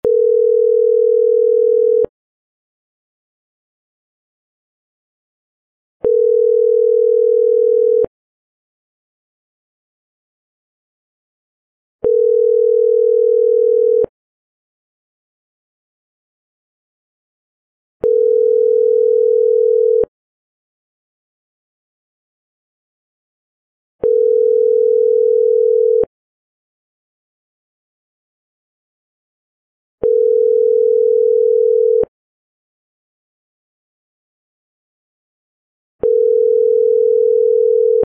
Dlinnyie_Gudki
Dlinnyie_Gudki.mp3